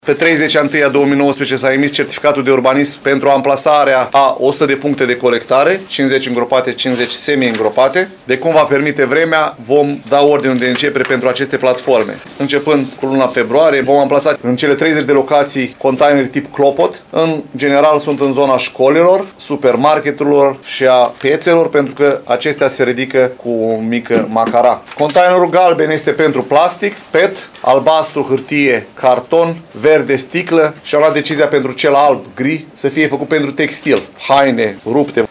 Viceprimarul LUCIAN HARȘOVSCHI a declarat astăzi că, potrivit contractului în vigoare, cele două firme vor amplasa 100 de platforme îngropate și semiîngropate, precum și 30 de containere tip clopot.